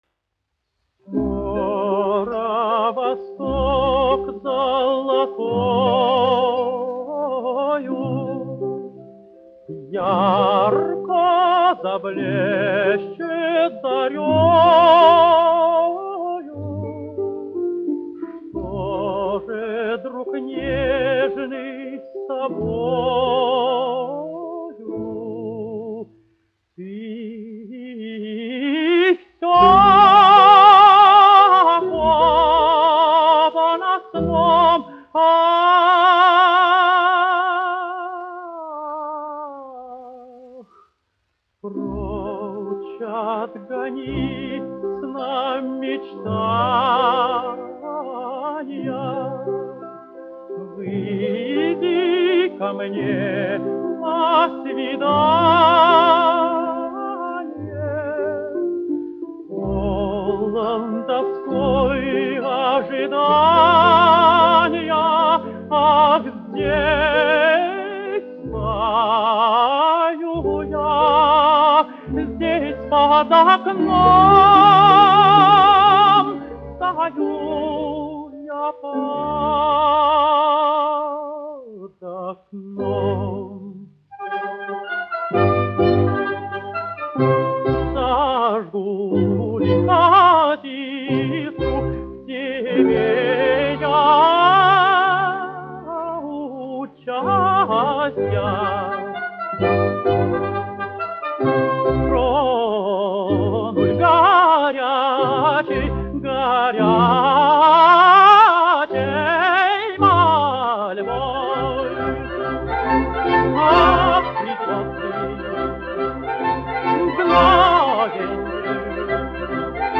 Виноградов, Георгий Павлович, 1908-1980, dziedātājs
Небольсин, Василий Васильевич, 1898-1958, diriģents
Большой театр СССР. Оркестр, izpildītājs
1 skpl. : analogs, 78 apgr/min, mono ; 25 cm
Operas--Fragmenti
Latvijas vēsturiskie šellaka skaņuplašu ieraksti (Kolekcija)